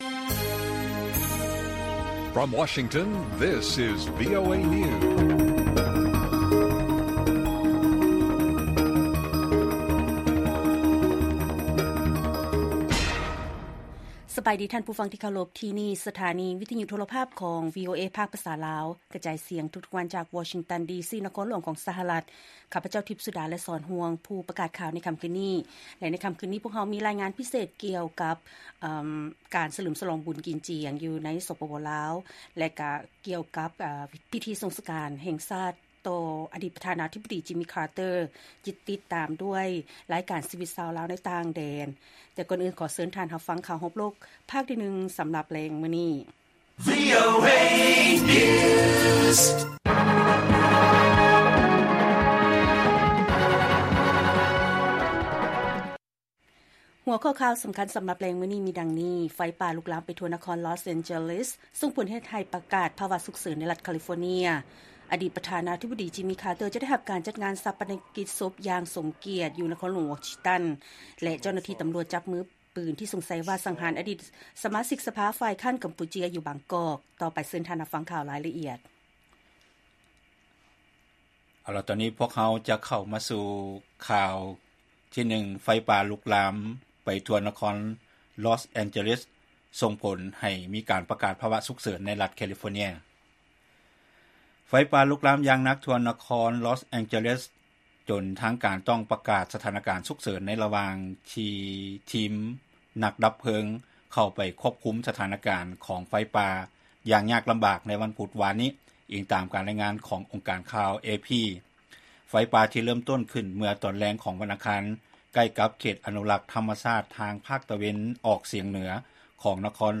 ລາຍການກະຈາຍສຽງຂອງວີໂອເອລາວ: ໄຟປ່າ ລຸກລາມໄປທົ່ວນະຄອນ ລອສ ແອນເຈີເລສ ສົ່ງຜົນໃຫ້ປະກາດພາວະສຸກເສີນໃນລັດຄາລີຟໍເນຍ